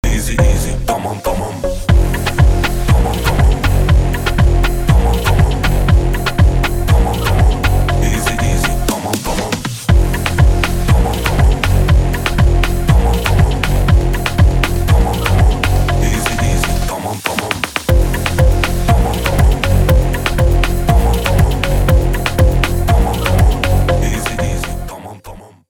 • Качество: 320, Stereo
remix
house
Четкий хаус